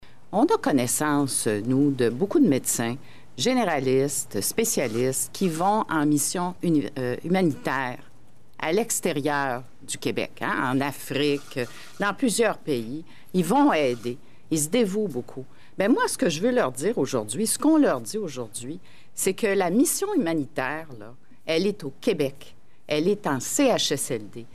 La ministre de la Santé, Danielle McCann, ajoute que plusieurs médecins participent à des missions humanitaires et que présentement, c’est au Québec qu’on a besoin de cette aide humanitaire.